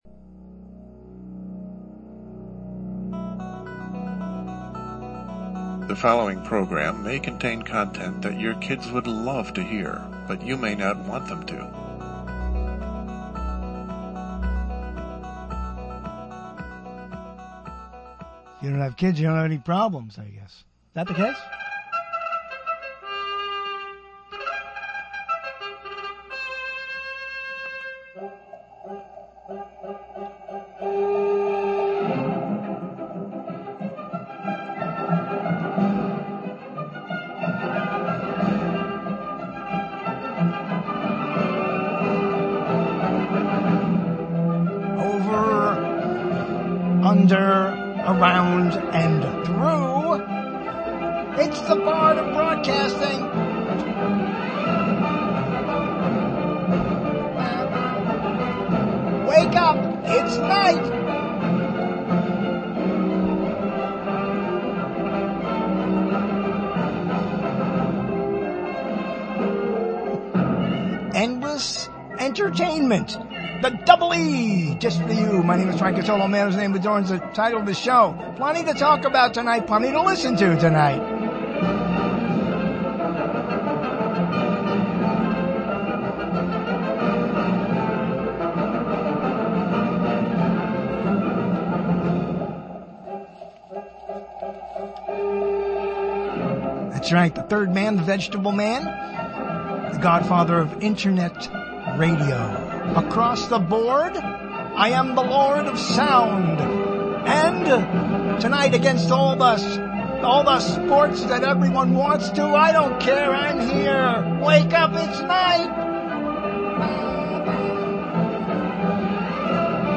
LIVE at 9:00 p.m. Eastern time, Oct. 20, we once again go one to one with listeners. Some modern topics hit the ground with the usual crash expected from a solo show. For one, the topic of Podcasts in 2016 will be addressed from the experience of our host and this show, which was developed before the Podcast craze and through the Podcast splurge for attention.